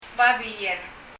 babier の b が [p]音になるのかな、と予想しましたが、比較的はっきりと[b]音に聞こえます。
« hospital 病院 eraser 消しゴム » paper 紙 babier [babiɛ(r)] babier の b が [p]音になるのかな、と予想しましたが、比較的はっきりと[b]音に聞こえます。